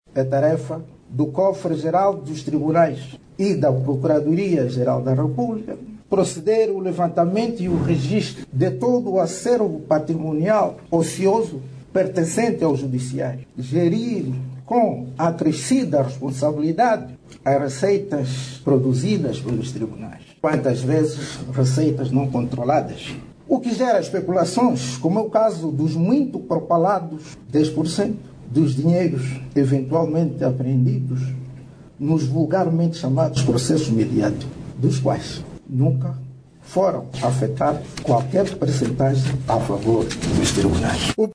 O Juiz Presidente do Tribunal Supremo, disse que os dez por cento dos valores apreendidos nos alegados processos mediáticos, nunca foram recebidos pelos tribunais angolanos. Joel Leonardo fez estas declarações durante a inauguração da sede dos órgãos executivos do Cofre dos Tribunais e da PGR.